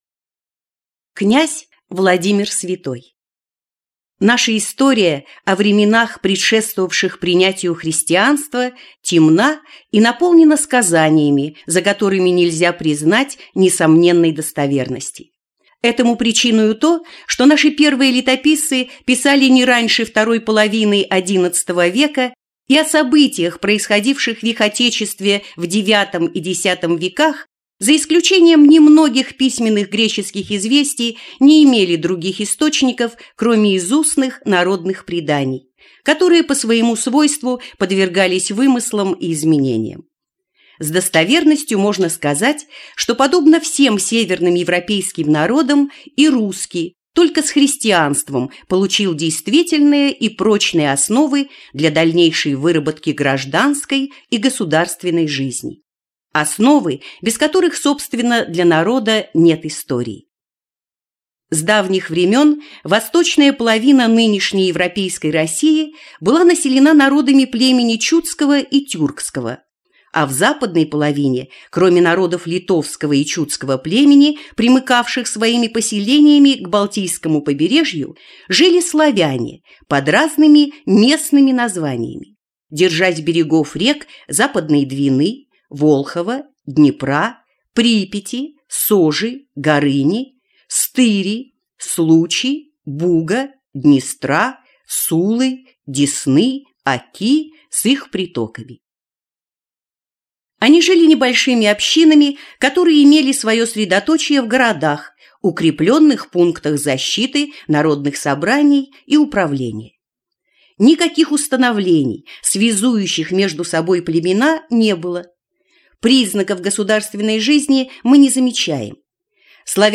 Аудиокнига Русская история. Том 1. Господство дома св. Владимира | Библиотека аудиокниг